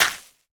Minecraft Version Minecraft Version snapshot Latest Release | Latest Snapshot snapshot / assets / minecraft / sounds / block / suspicious_gravel / place4.ogg Compare With Compare With Latest Release | Latest Snapshot